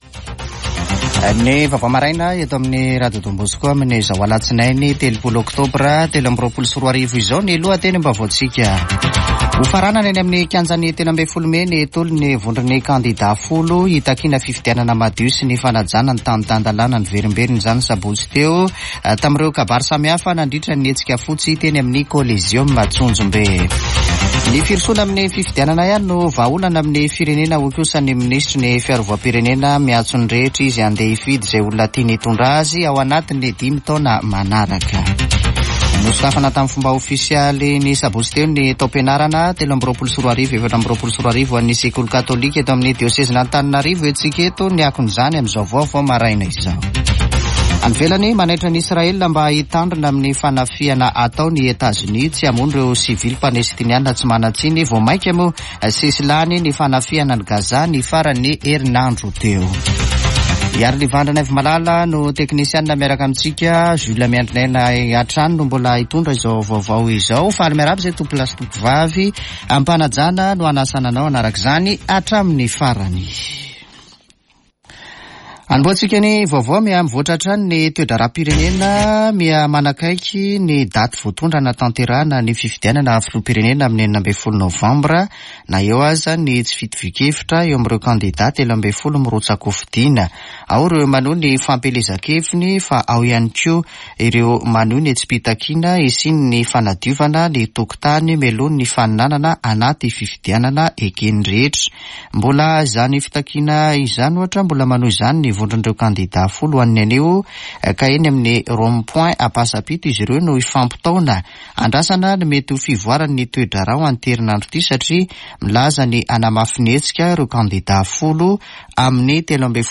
[Vaovao maraina] Alatsinainy 30 ôktôbra 2023